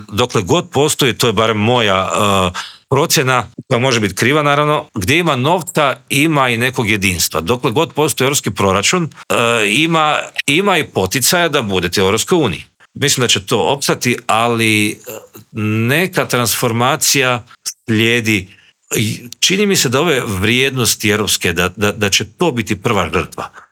Intervjua Media Servisa